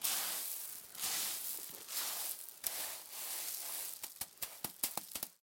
Звуки шагов по песку